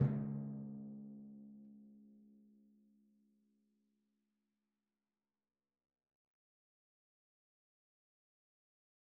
Timpani3_Hit_v3_rr1_Sum.wav